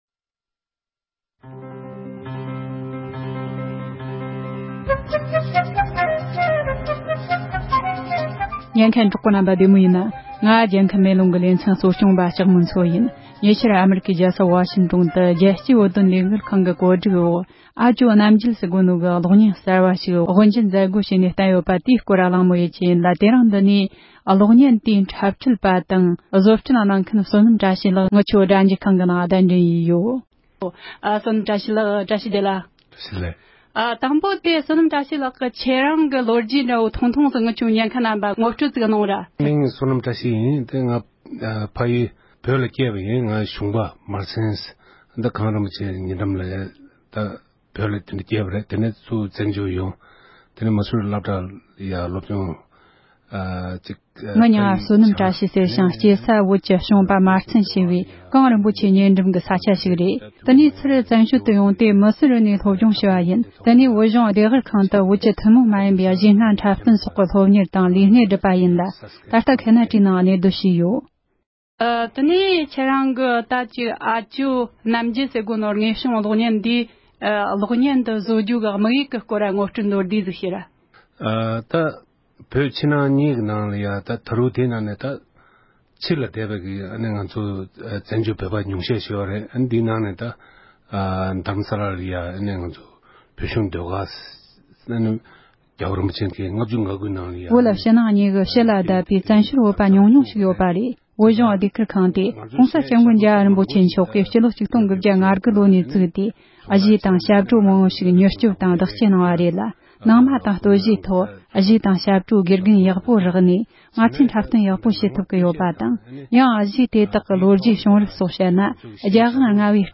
བཀའ་འདྲི་ཞུས་པར་གསན་རོགས་གནོངས༎